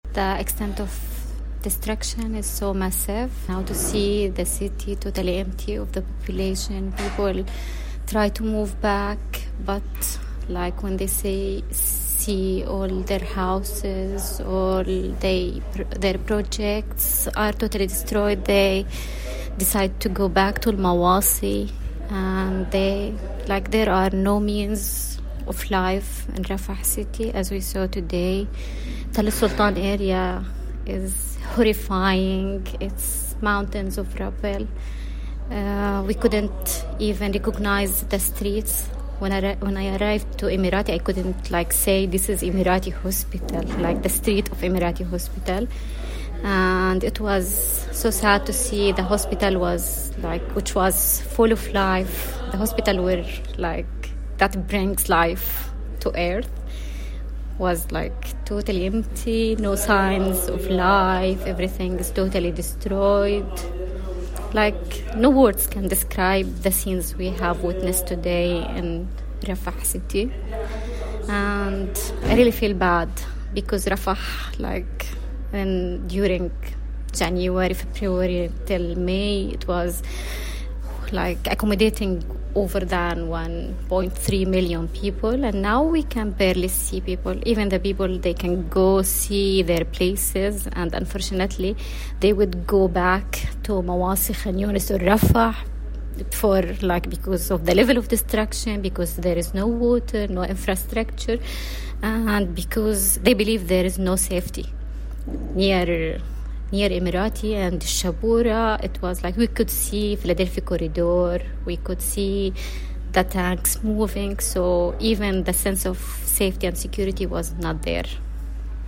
Voice note mp3: